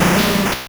Cri de Grolem dans Pokémon Or et Argent.